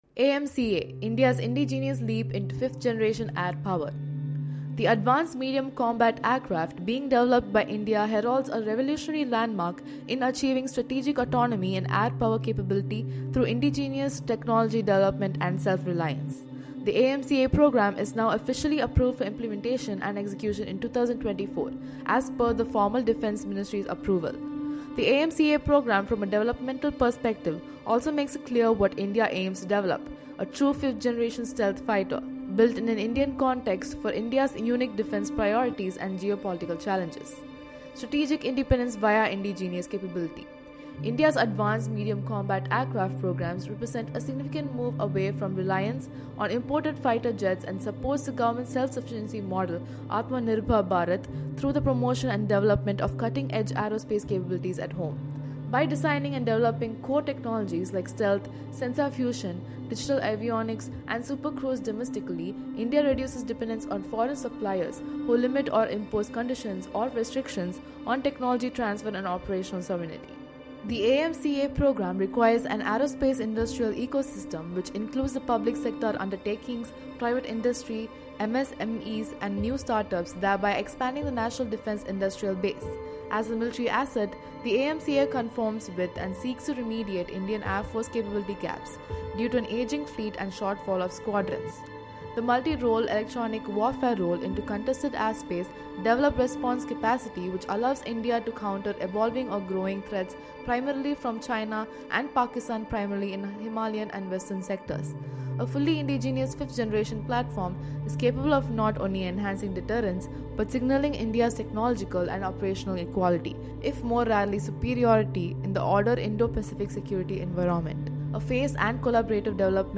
Audio Book
AMCA-AUDIO-BOOK2.mp3